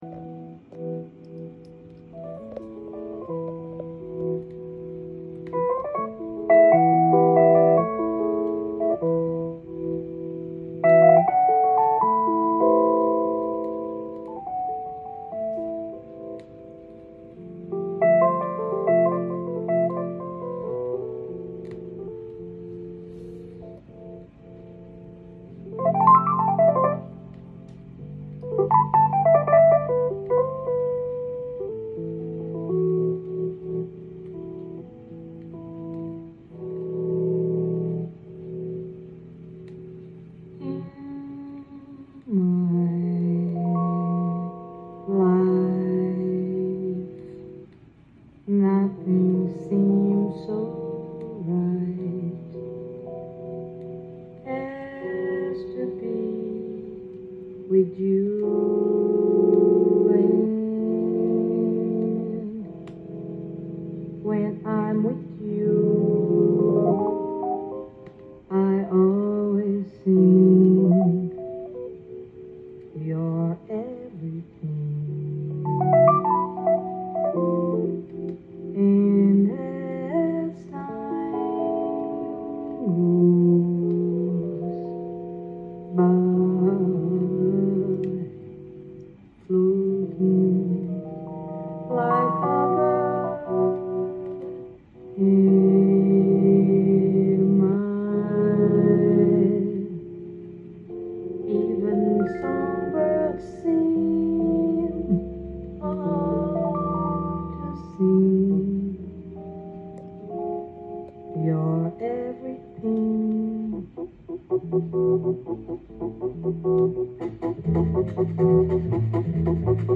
店頭で録音した音源の為、多少の外部音や音質の悪さはございますが、サンプルとしてご視聴ください。
浮遊感漂うトロピカルなブラジリアン・フロア・ジャズ